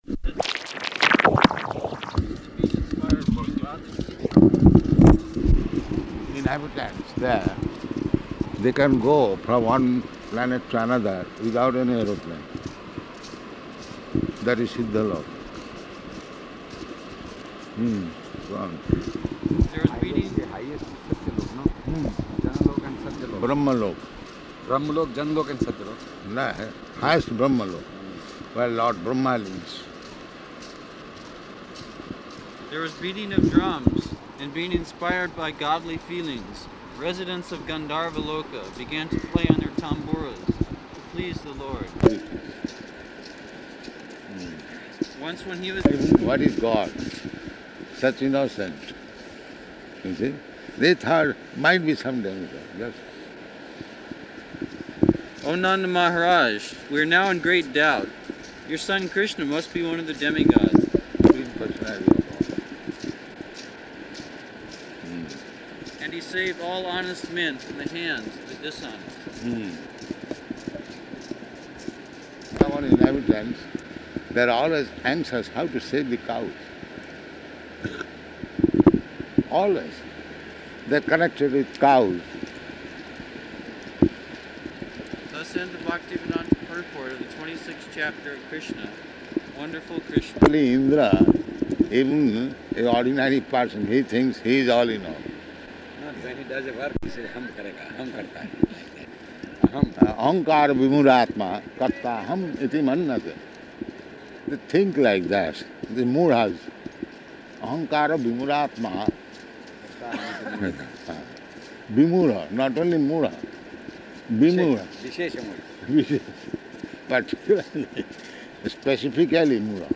Type: Walk
Location: Bombay